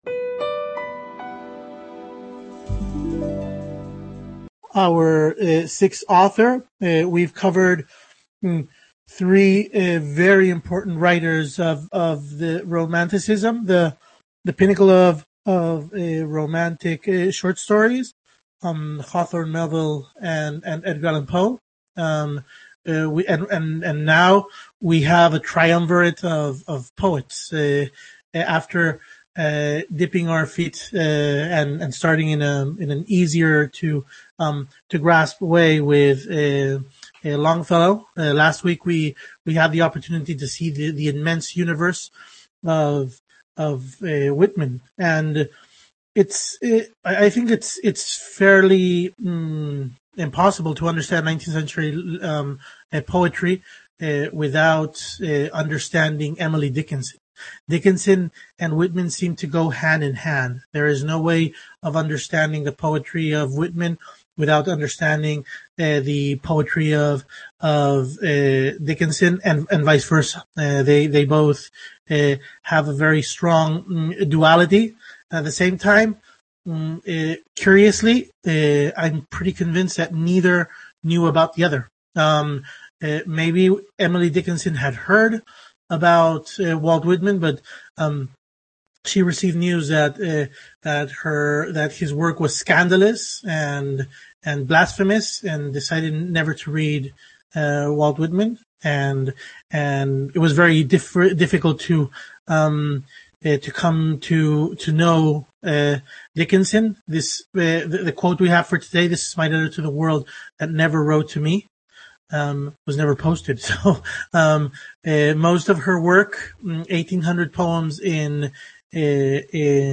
Webconference held on March 25th 2020.